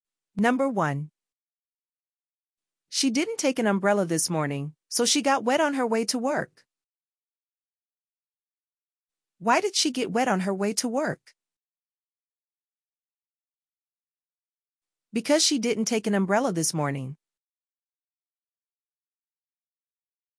① ネイティブ・スピードで英文を聞く
② ２秒後に質問が１つ流れるので答える
③ ３秒後にその質問に対す答えが流れるので、合っていたか確認する